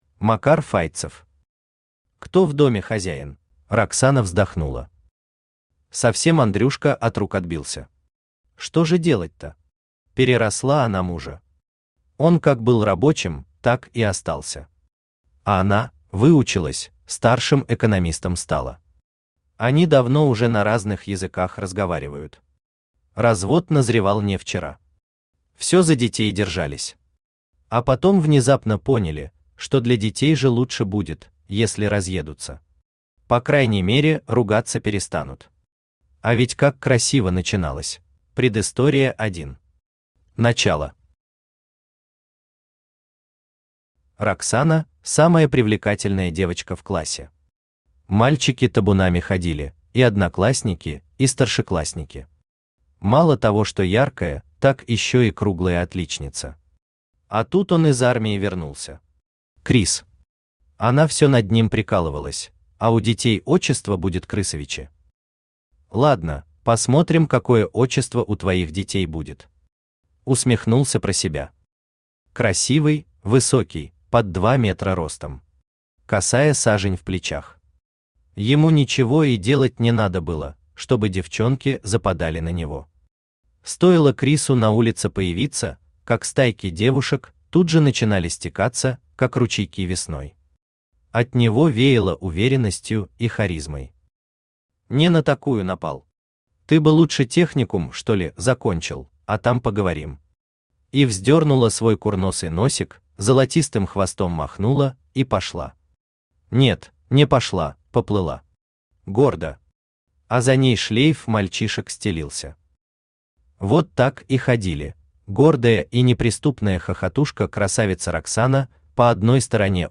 Аудиокнига Кто в доме хозяин?
Автор Макар Файтцев Читает аудиокнигу Авточтец ЛитРес.